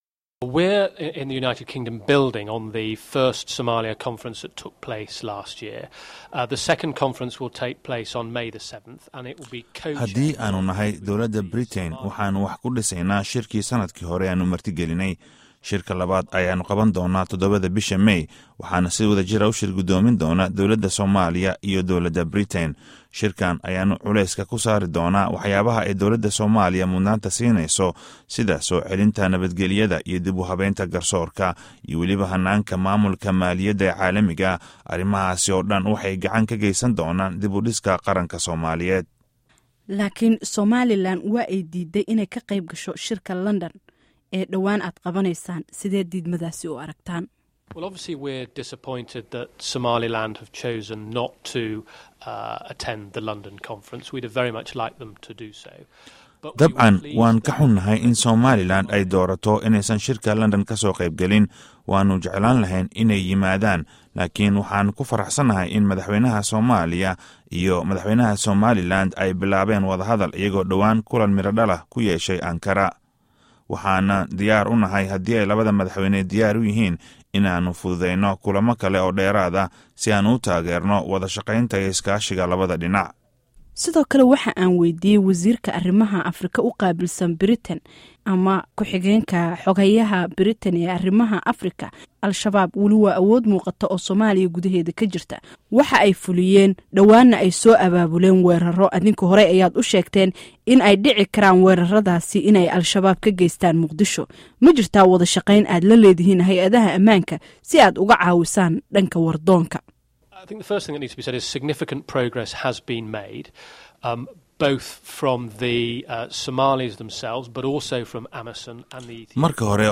Wasiirka Arrimaha Africa ee Britain, Mark Simons oo wareysi siiyey VOA ayaa sheegay in uu ka xun yahay in Somaliland ay ka baaqato shirka bisha May.
Wareysiga Mark Simons